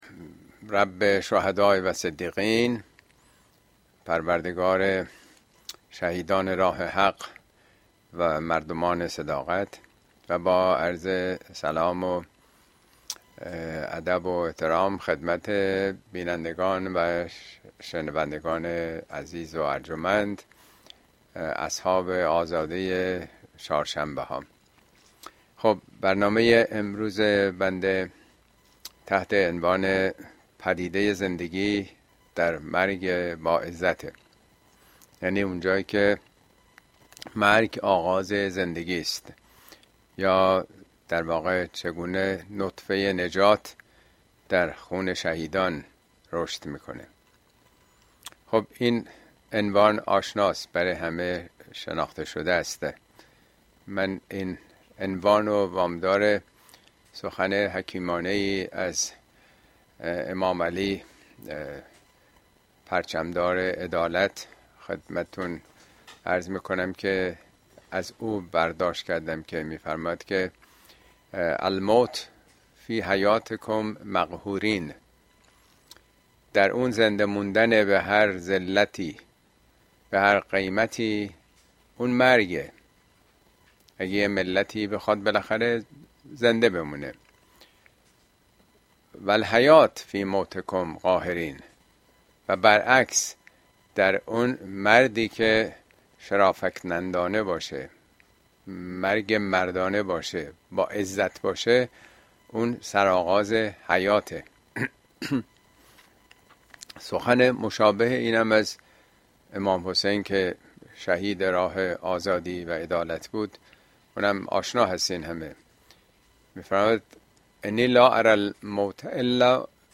Speech
` موضوعات اجتماعى اسلامى !پدیده زندگی در مرگ با عزت اين سخنرانى به تاريخ ۸ می ۲۰۲۴ در كلاس آنلاين پخش شده است توصيه ميشود براىاستماع سخنرانى از گزينه STREAM استفاده كنيد.